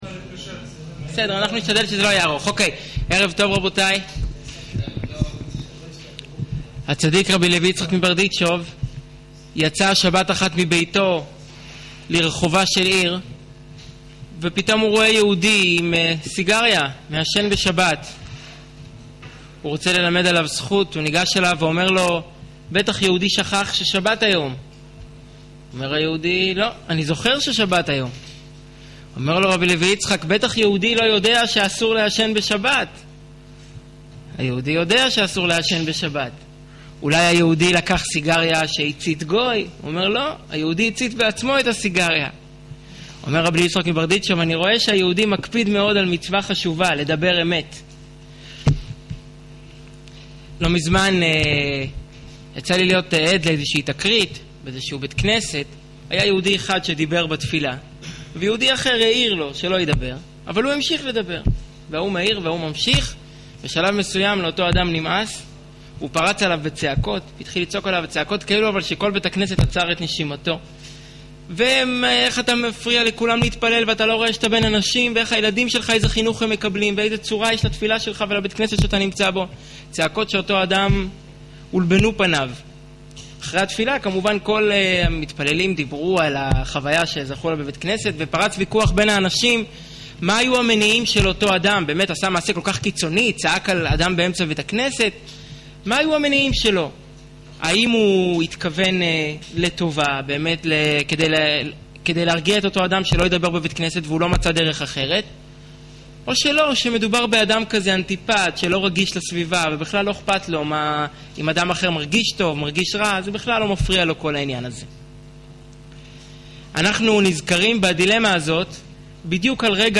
האם פנחס צדק כשהרג את זמרי? ● שיעור וידאו
שנמסר בביהכנ"ס חב"ד בראשל"צ